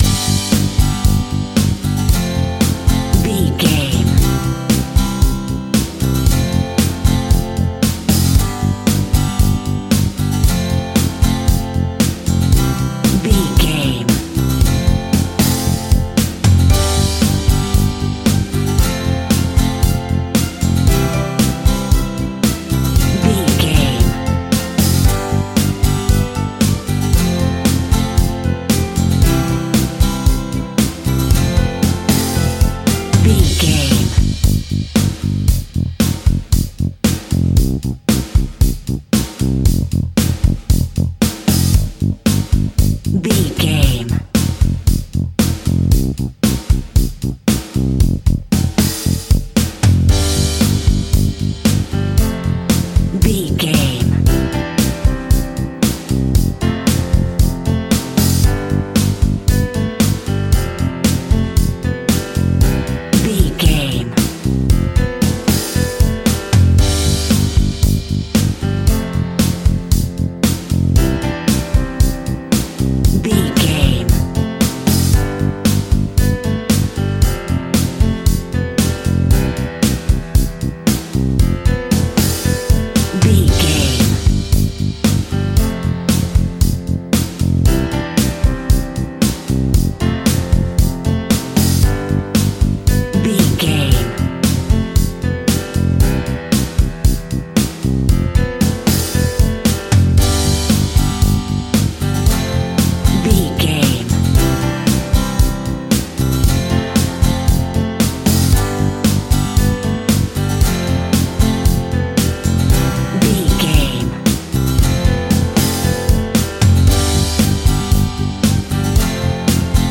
Ionian/Major
indie pop
pop rock
sunshine pop music
drums
bass guitar
electric guitar
piano
hammond organ